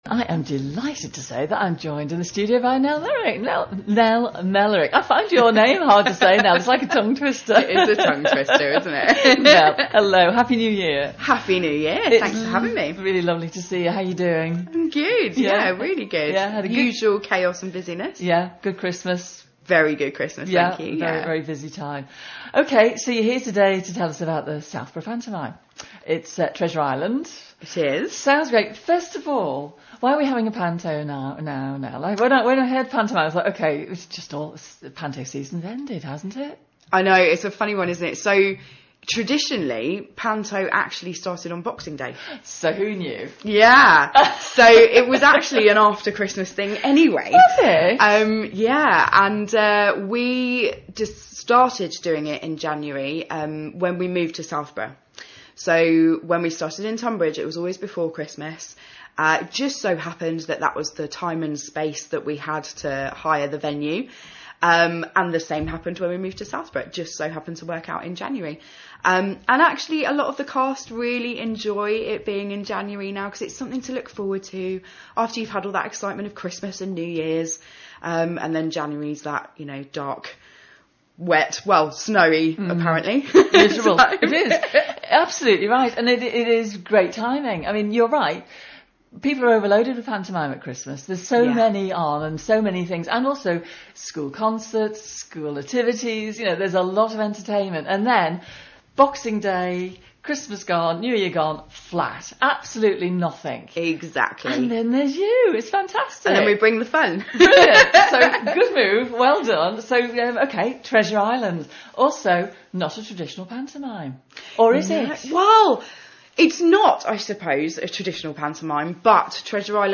For more details on this event go to: Winter Lanterns Tunbridge Wells Listen to the interview here: Listen to this audio